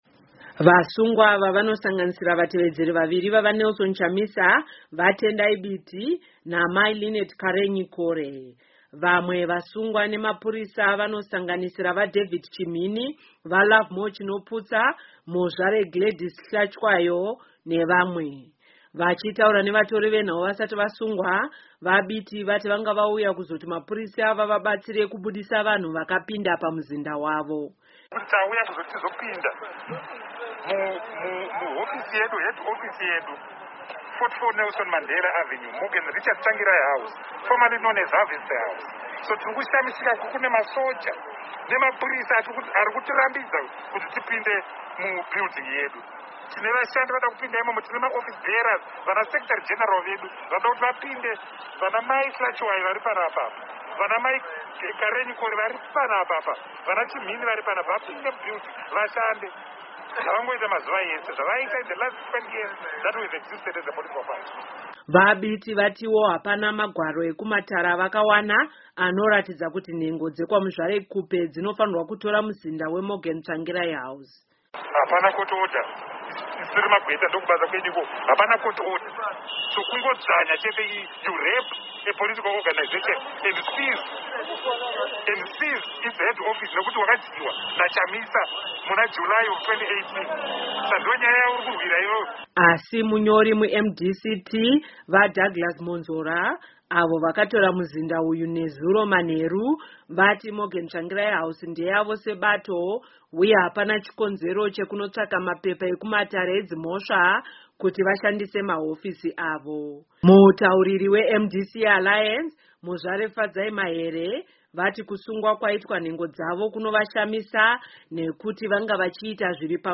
VaTendai Biti vachitaura vasati vasungwa.